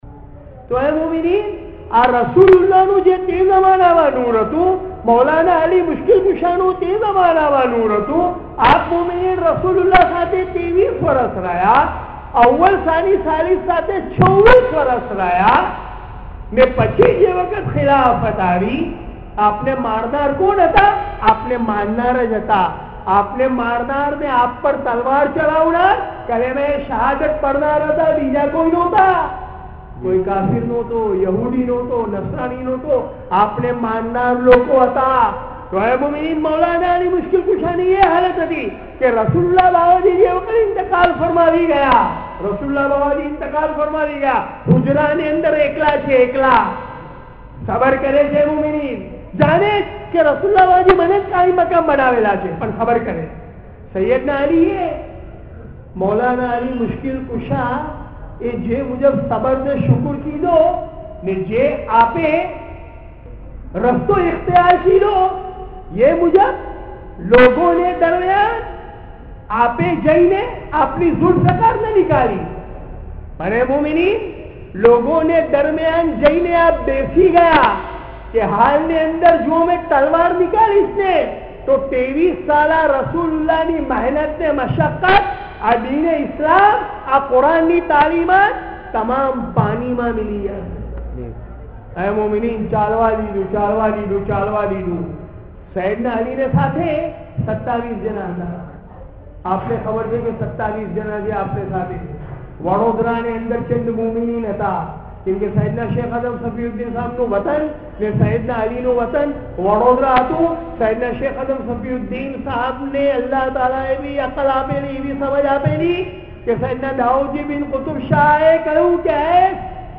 Bayaan